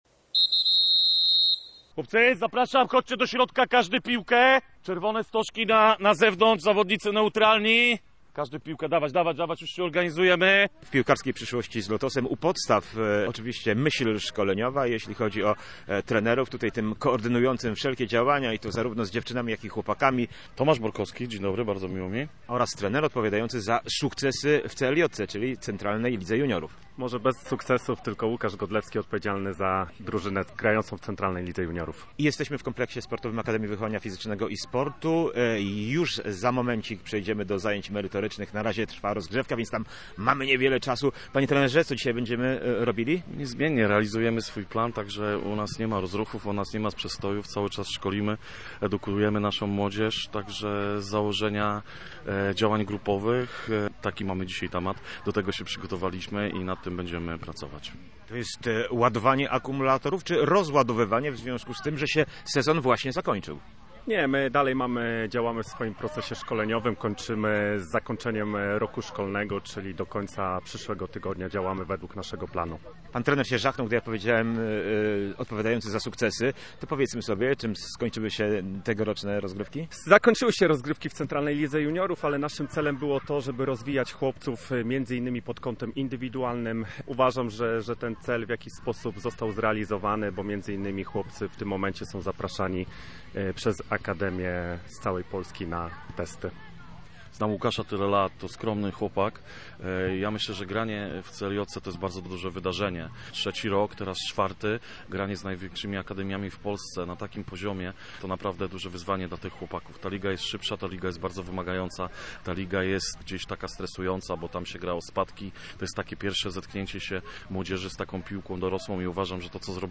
Obu panów odwiedziliśmy na treningu